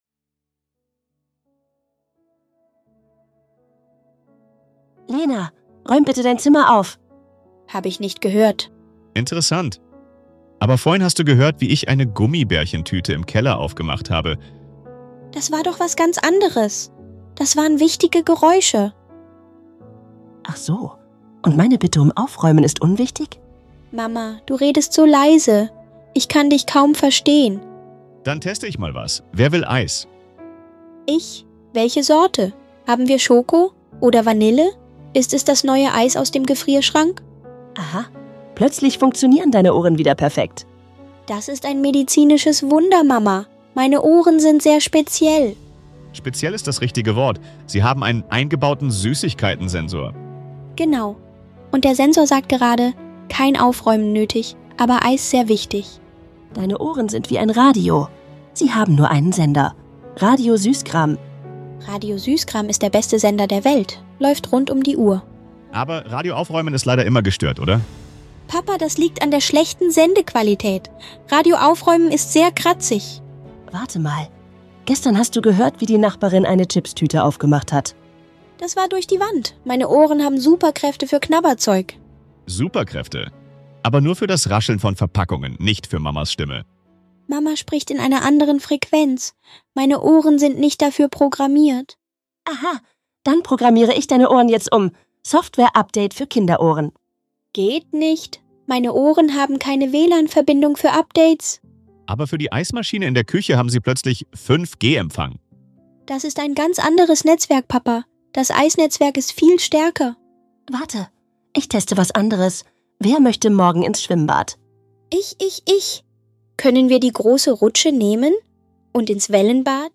lustigen Gespräch